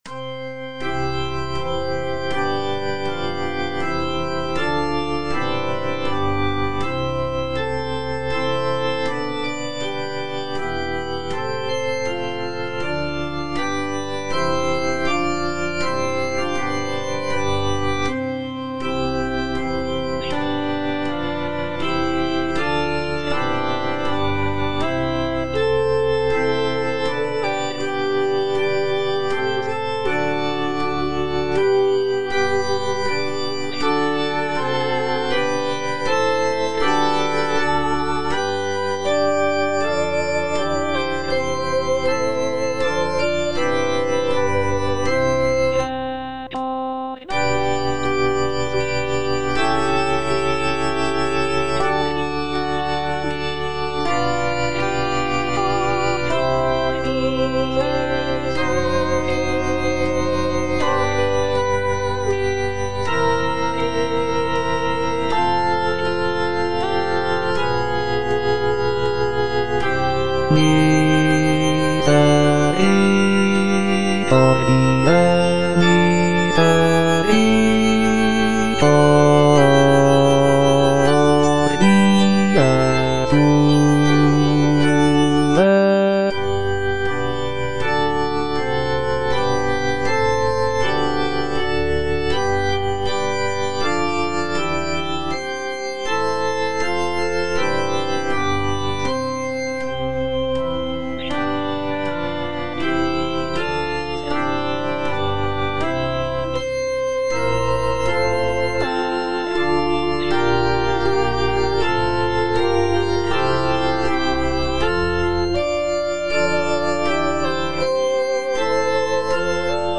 Bass (Voice with metronome) Ads stop
sacred choral work